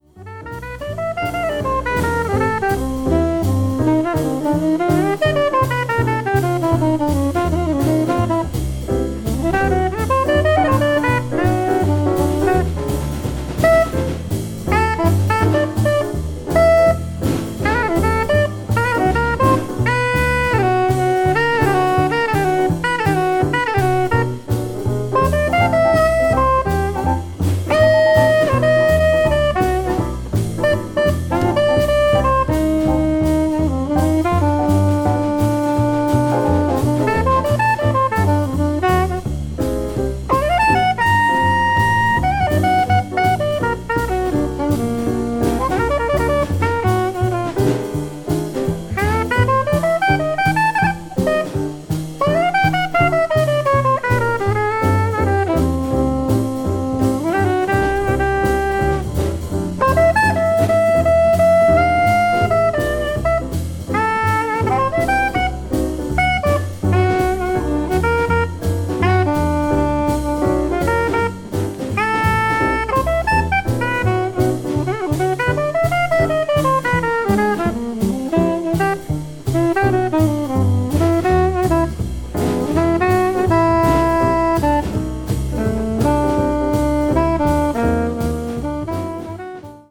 かなりストレートな演奏ですが、後に大きく開花する才能の片鱗をマジマジと感じさせます。